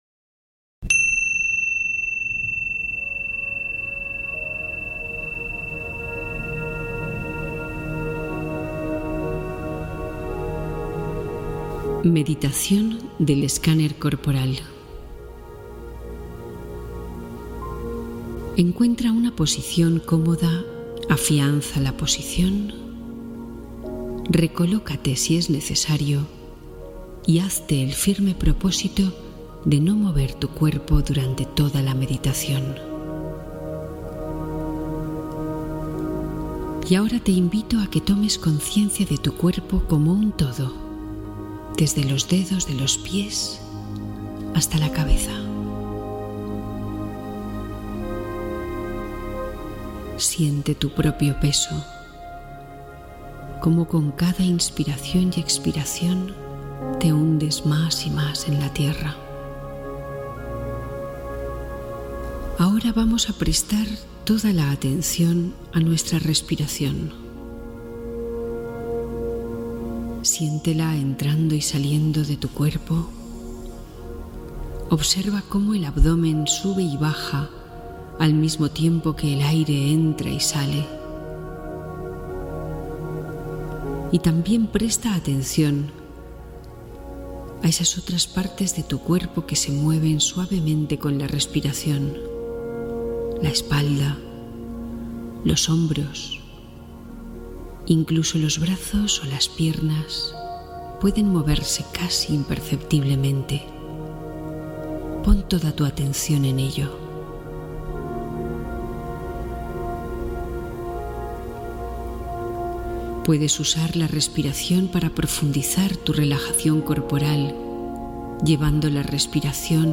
Escáner Corporal Completo: Meditación para Relajar Todo el Sistema